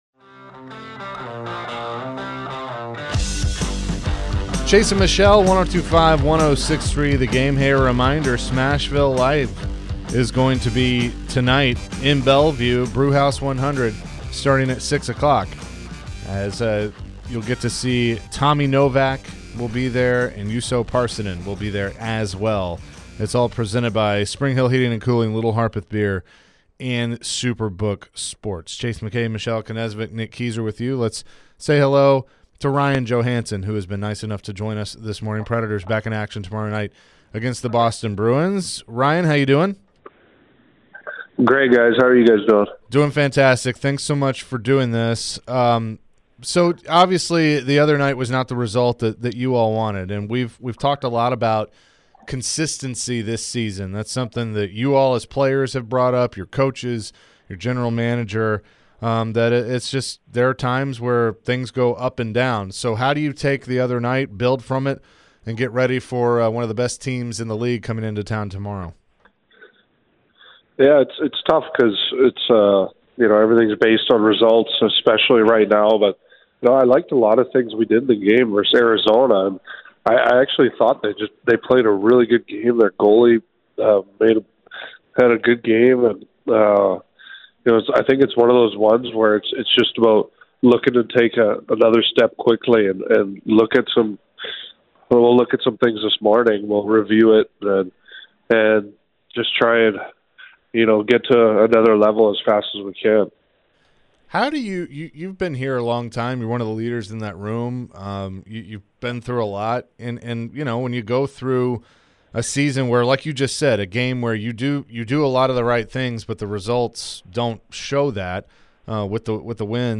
Ryan Johansen Interview (2-15-23)